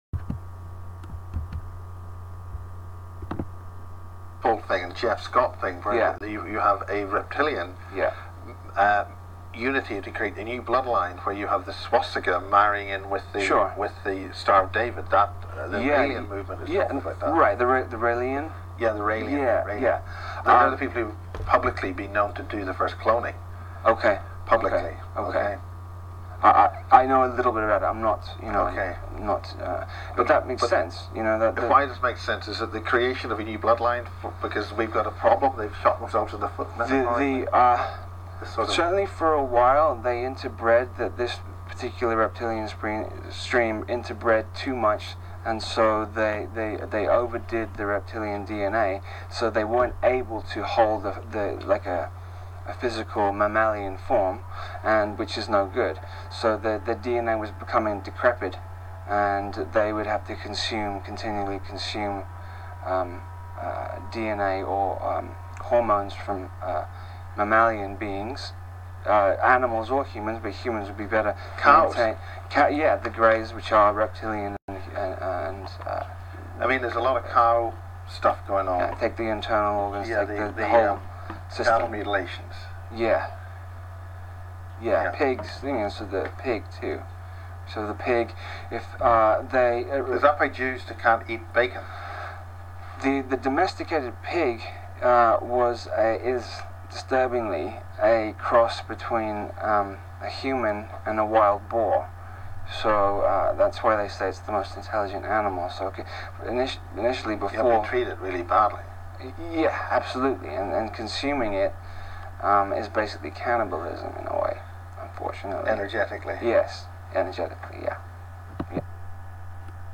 Interview Clip